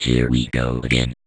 VVE1 Vocoder Phrases
VVE1 Vocoder Phrases 26.wav